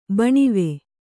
♪ baṇive